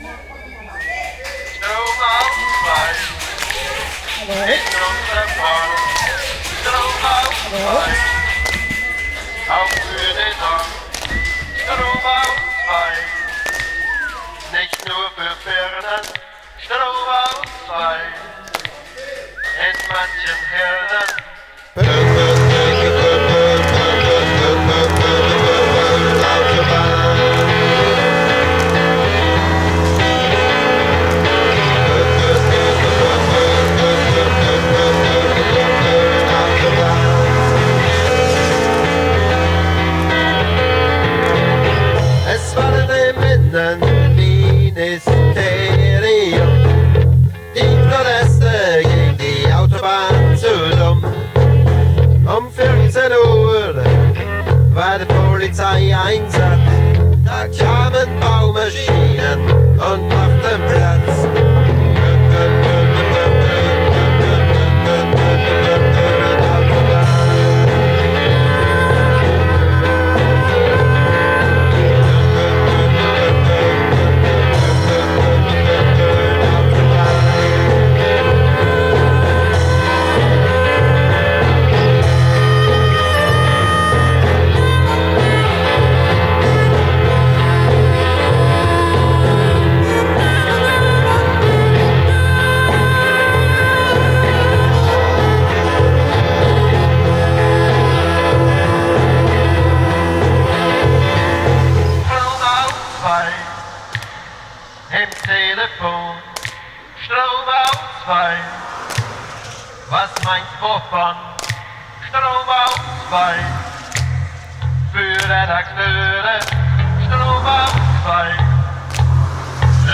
Genre: Freie Musik - Rock